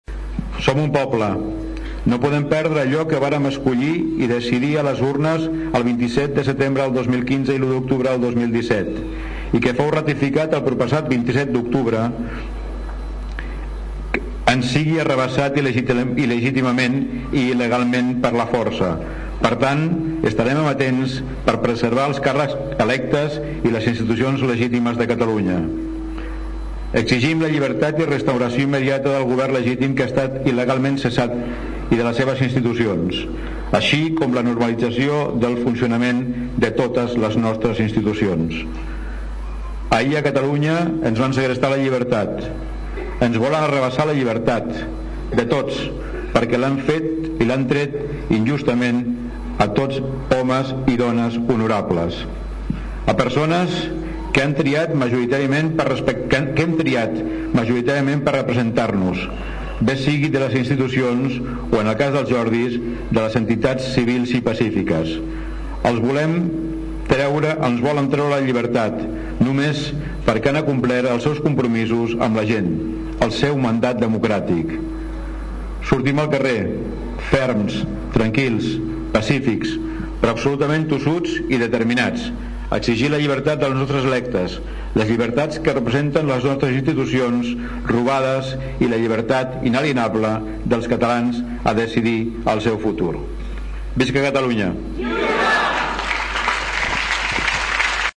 Concentracio 3-OLa plaça de l’Ajuntament es va omplir divendres al vespre de gom a gom per rebutjar l’empresonament preventiu de 8 consellers de la Generalitat cessats per l’aplicació de l’article 155.
Escoltem el fragment del manifest llegit per l’alcalde.
alcalde-manifest-3N.mp3